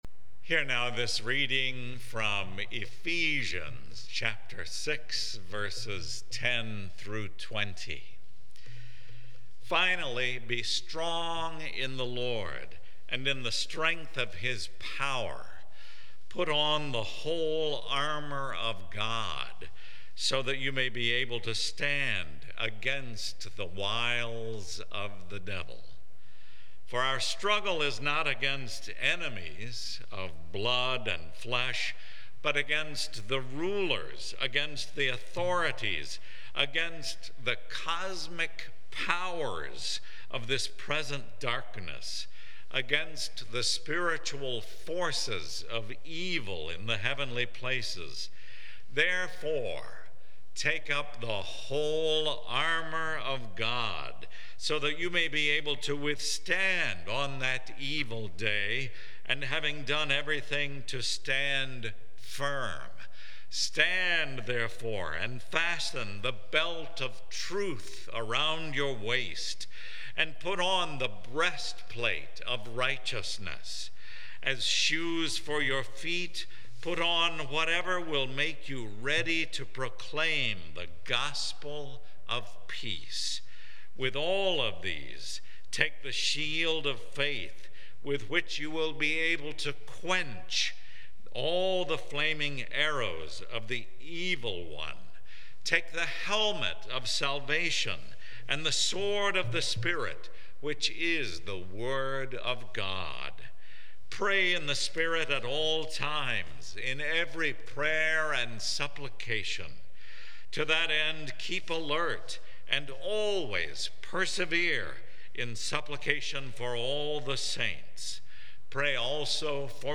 Sermon Reflections: How is the metaphor of armor, as used in Ephesians, used to explain our spiritual battle? The sermon discusses each piece of armor mentioned in Ephesians.